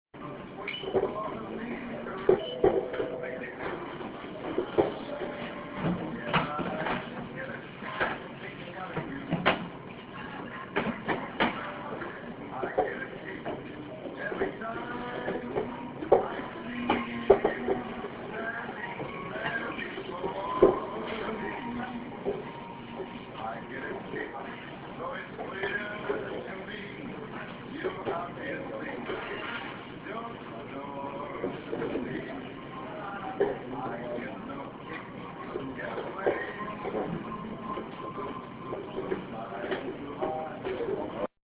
FieldRecording1 – Hofstra Drama 20 – Sound for the Theatre
Location: Barnes and Noble Cafe